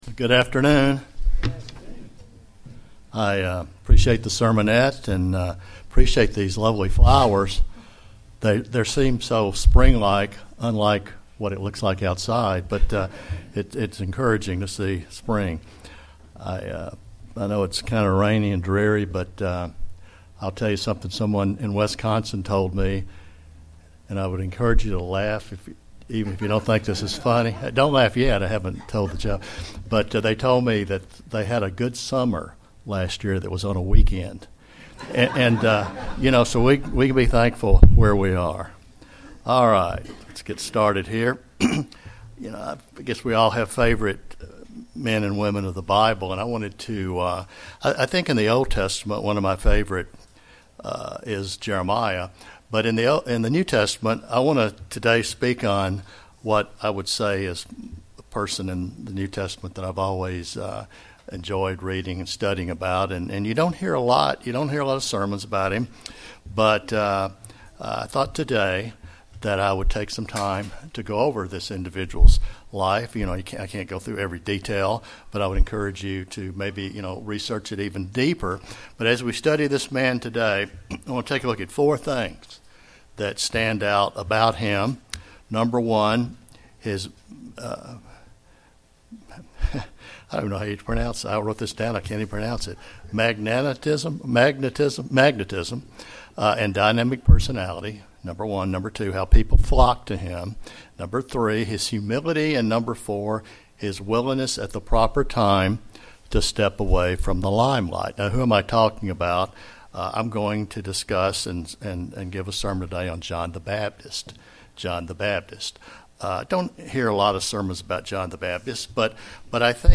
In this sermon we will examine the life of John the Baptist and what we can learn from his life.
Given in Huntsville, AL